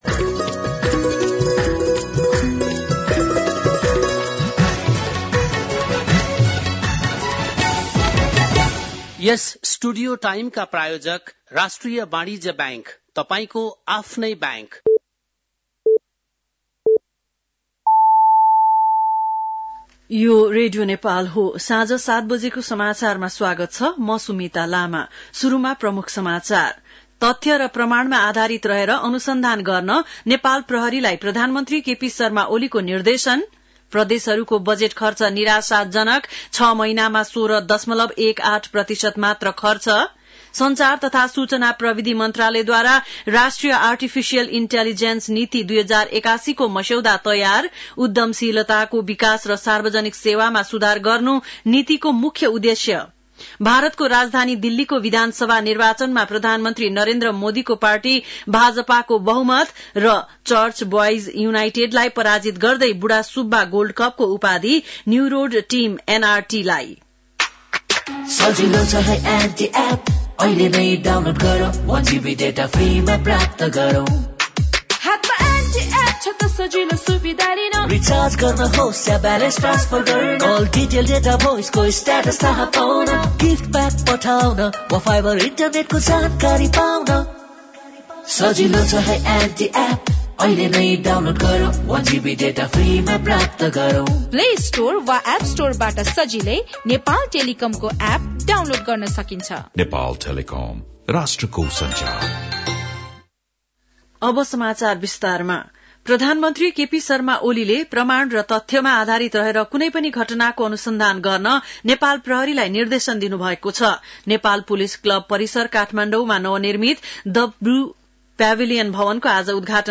बेलुकी ७ बजेको नेपाली समाचार : २७ माघ , २०८१
7-pm-news.mp3